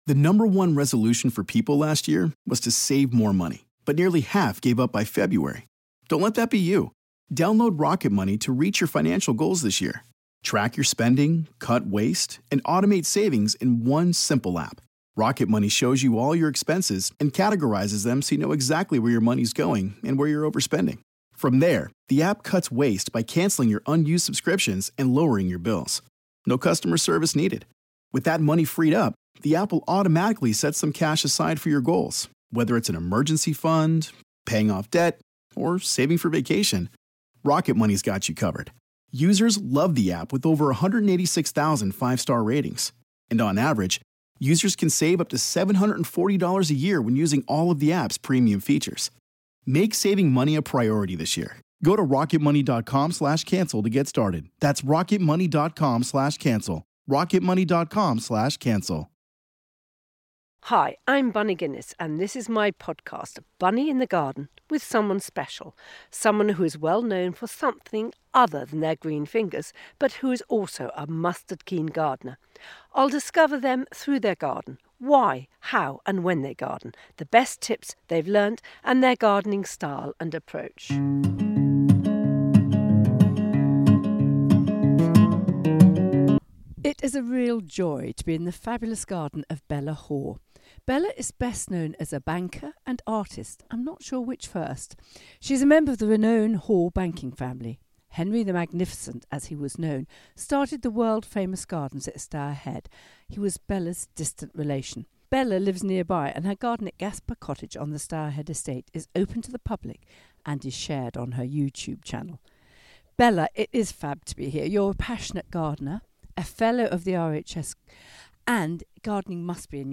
in her magnificent garden